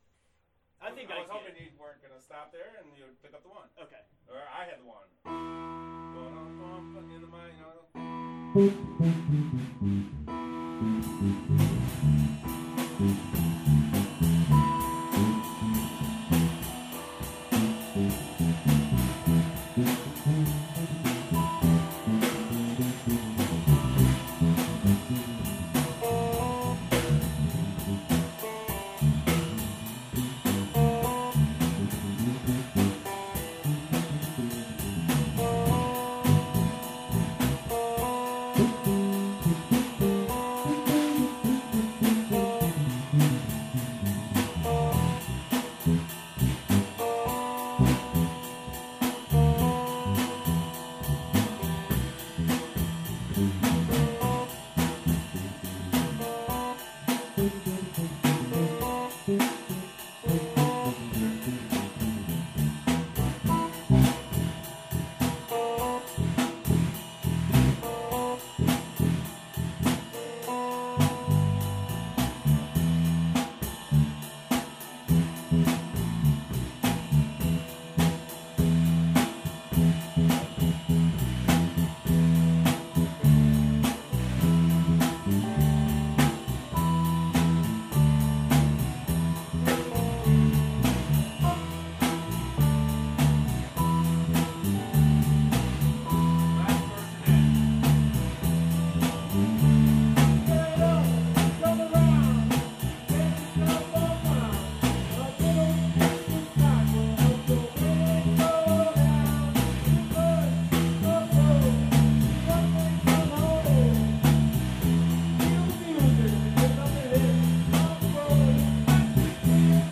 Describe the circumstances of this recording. Rehearsal at Denver Drums